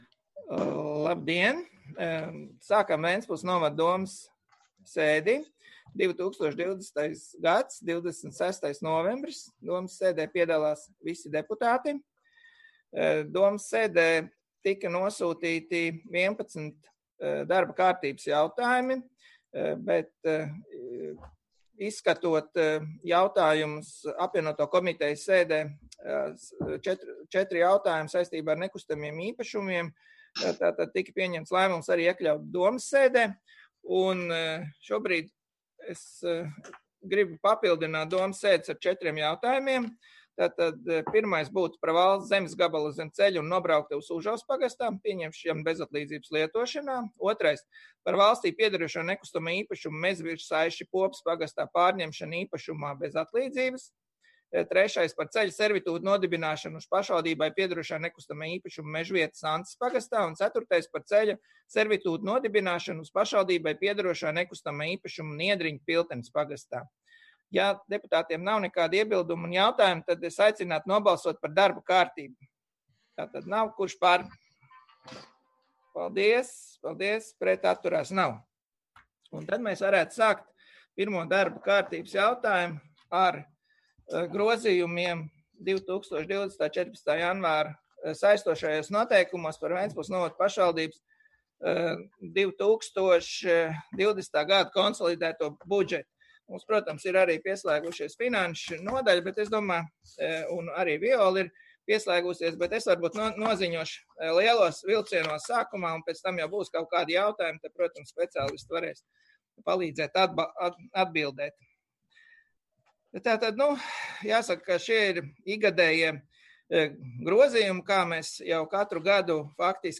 Domes sēdes audioieraksts